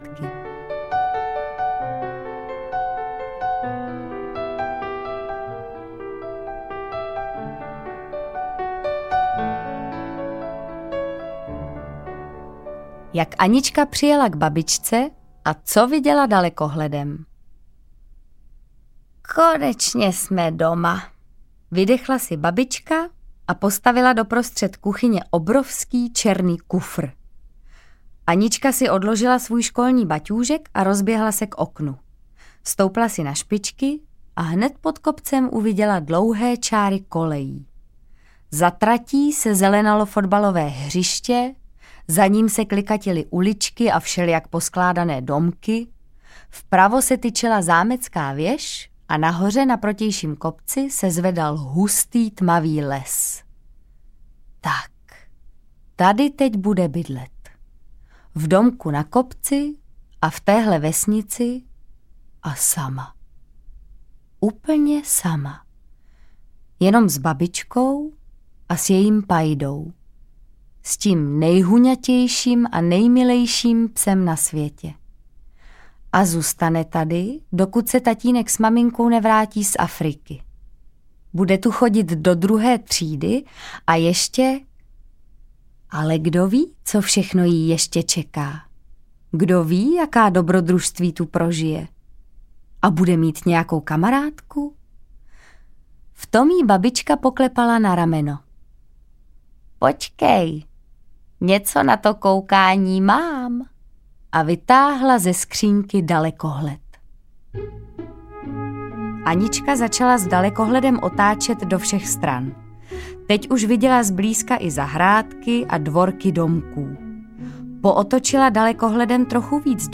Audio knihaAnička a její kamarádky
Ukázka z knihy
• InterpretMartha Issová